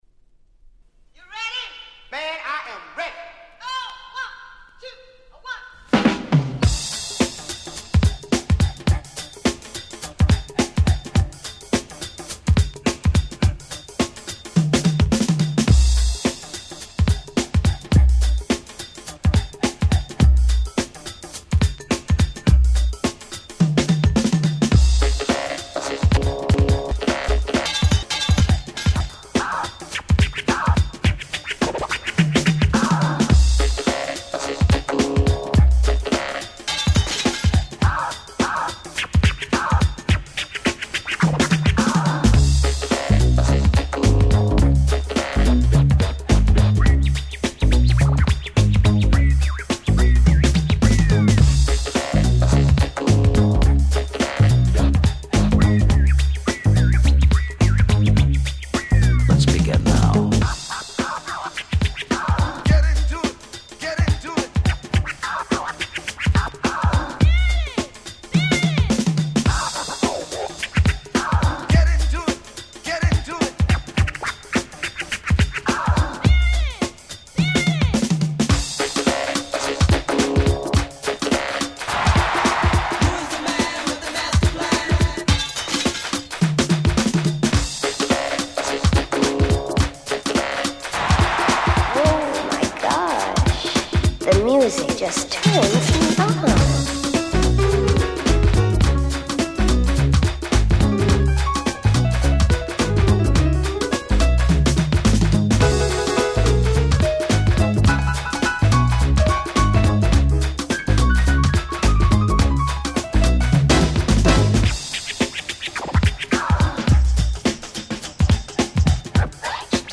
87' Super Nice Jazzy Break !!